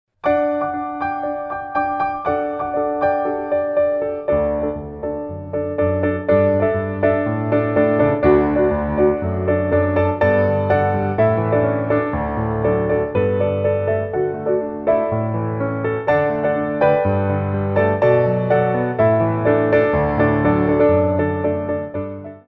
Medium Allegro 1
4/4 (8x8)